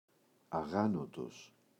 αγάνωτος [a’γanotos]